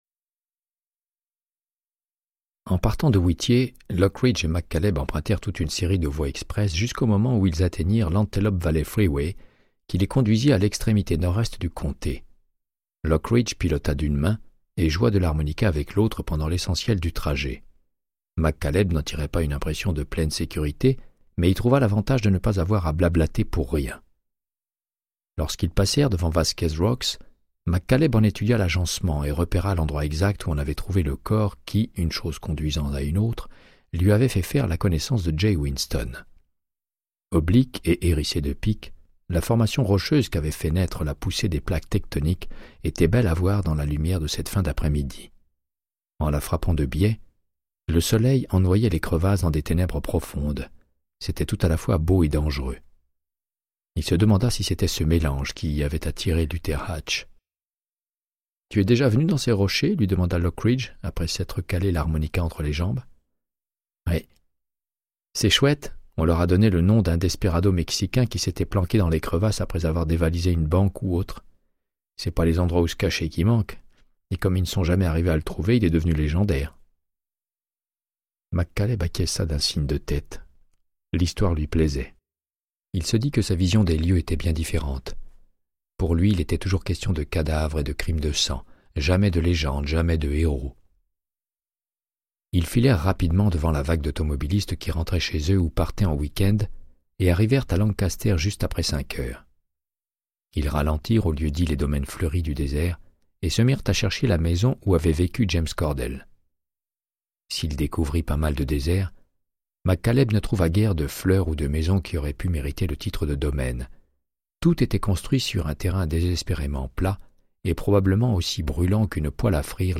Audiobook = Créance de sang, de Michael Connellly - 81